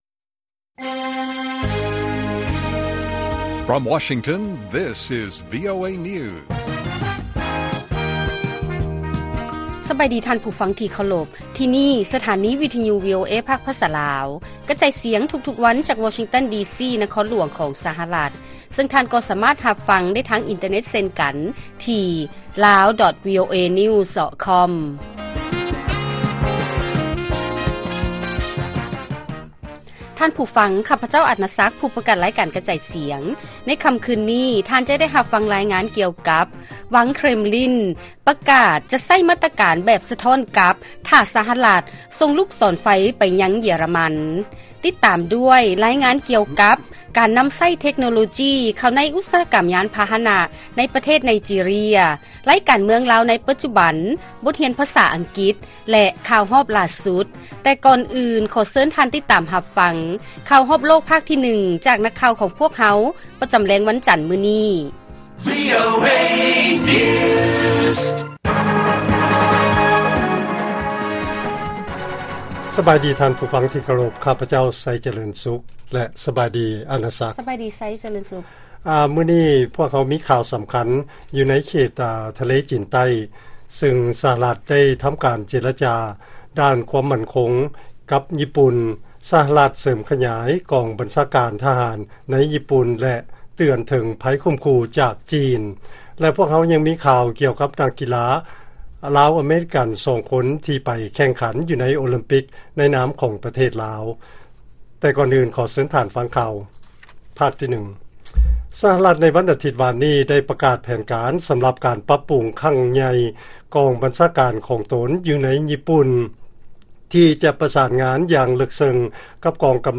ລາຍການກະຈາຍສຽງຂອງວີໂອເອ ລາວ: ວັງ ເຄຣມລິນ ປະກາດຈະໃຊ້ ‘ມາດຕະການແບບສະທ້ອນກັບ’ ຖ້າສະຫະລັດ ສົ່ງລູກສອນໄຟ ໄປຍັງເຢຍຣະມັນ.